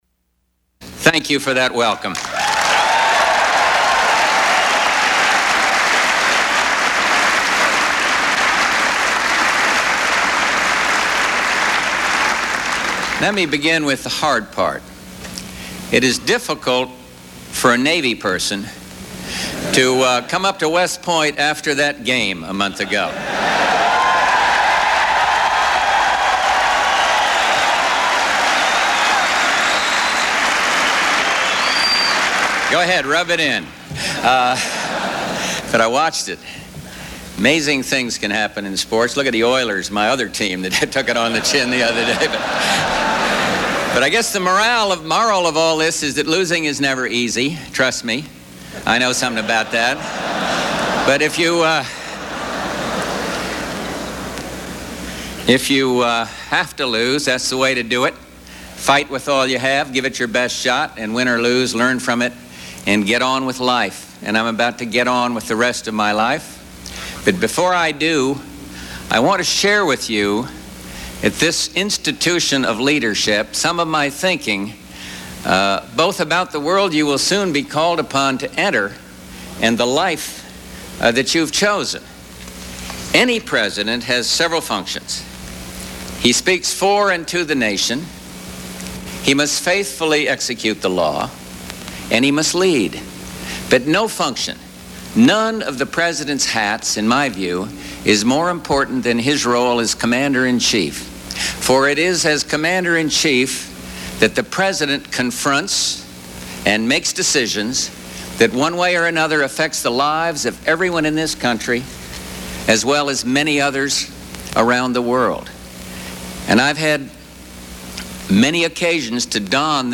U.S. President George Bush addresses the Corps of Cadets at the United States Military Academy in West Point, NY